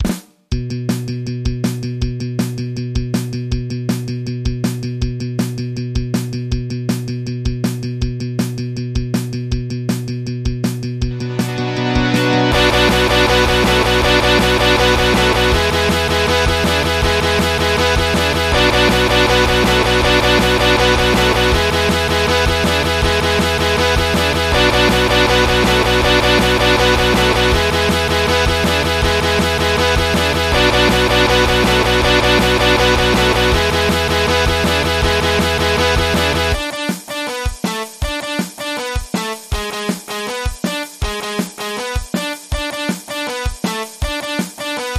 MIDI · Karaoke